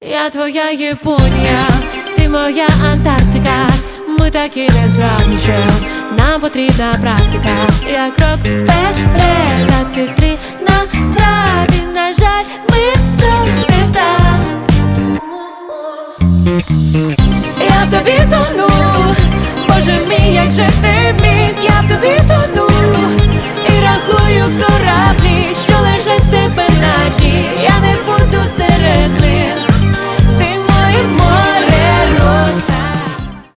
dance pop
synth pop